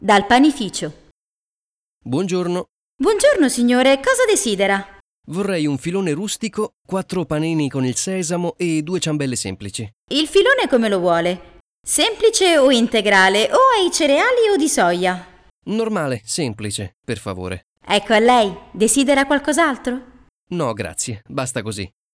hasznos párbeszédek audióval, 2024 augusztus-szeptemberi szám